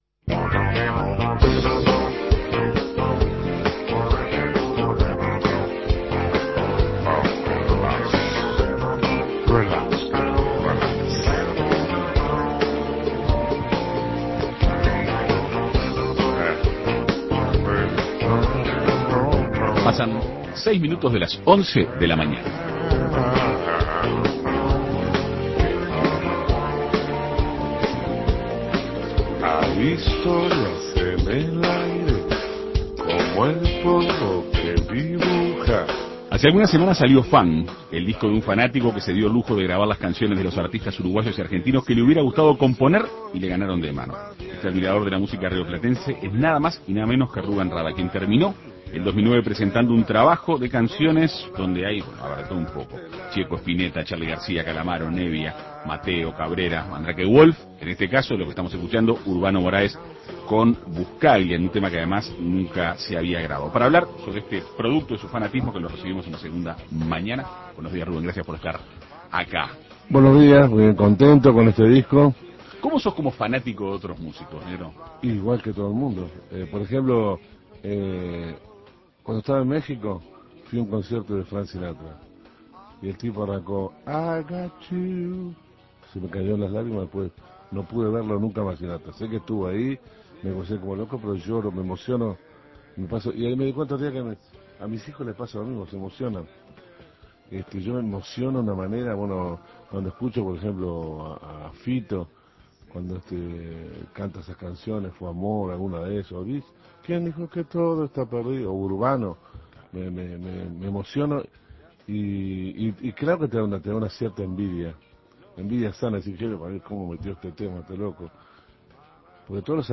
En Perspectiva Segunda Mañana entrevistó a Rubén Rada, quien terminó el 2009 presentando un trabajo de 16 canciones, con composiciones de Spinetta, Gieco, Charly, Calamaro, Nebbia, Mateo, Cabrera y Mandrake Wolf entre otros.